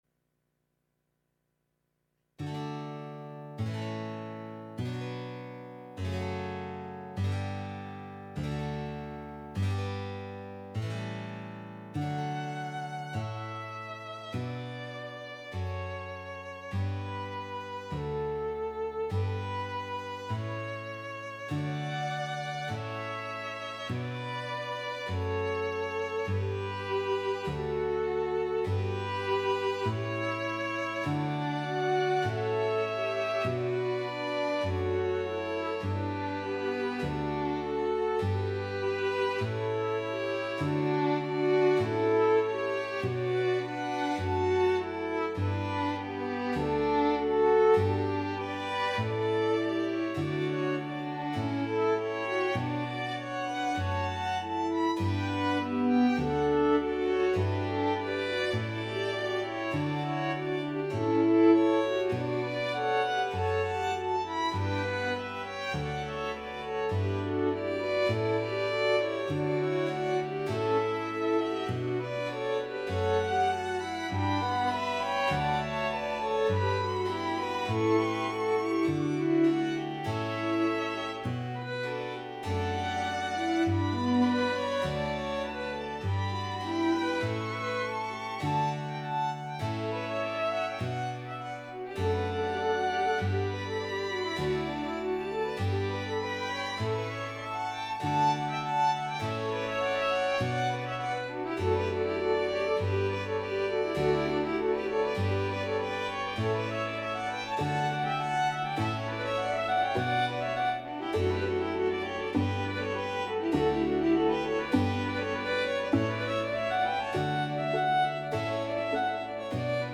Instrumentation: 3 x Violin, cello, (viola part included)
String Quartet (or 3 x Violin and cello)
This edition makes interesting use of staccato and
pizzicato over the familiar Basso ostinato.